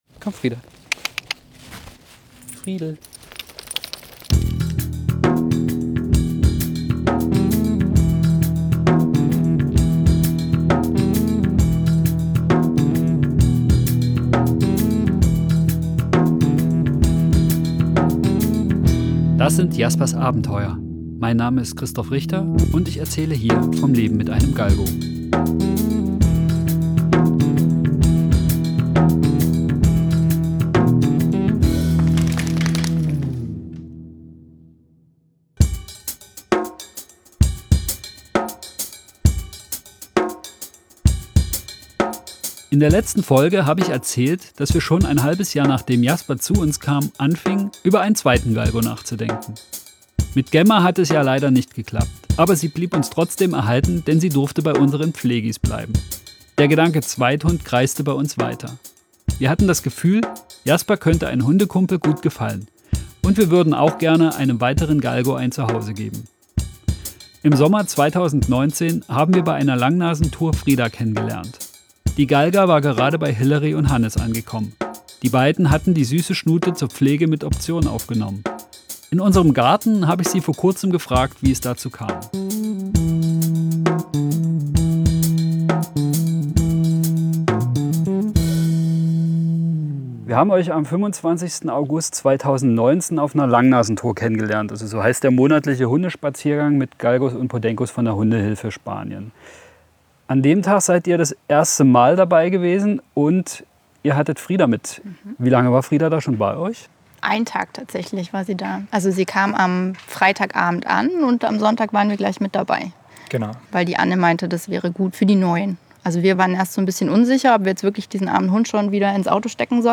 Immer wieder kommen dabei Stimmen von Tierschützer*innen aus Spanien zu Wort.
Dort habe ich ein Audiotagebuch geführt und insgesamt über 10 Stunden Tonaufnahmen gemacht.